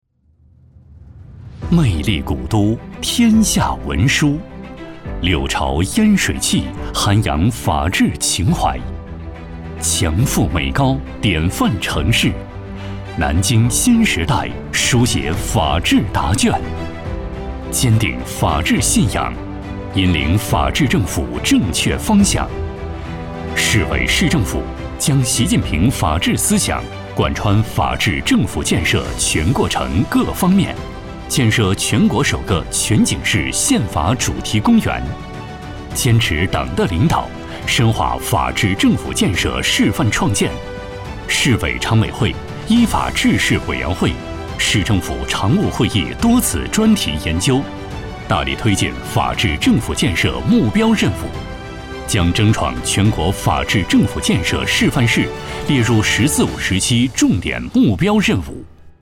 国语配音
男174-专题-.司法局汇报片.mp3